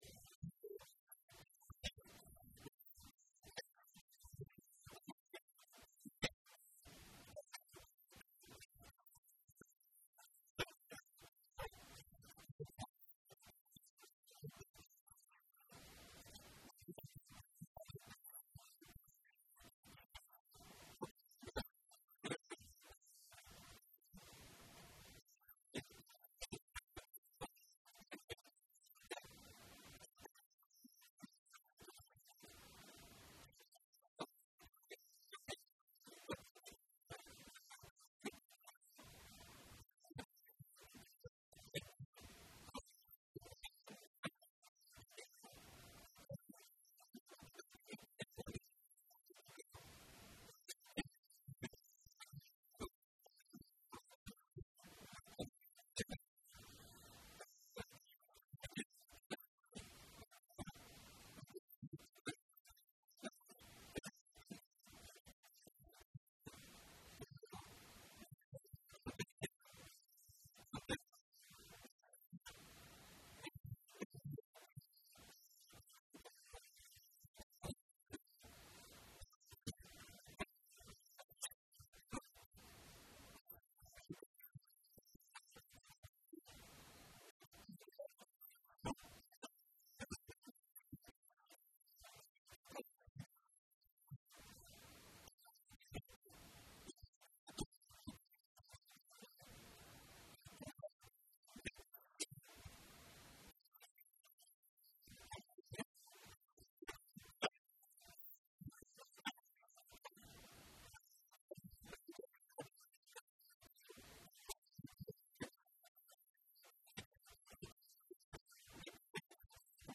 Entretien !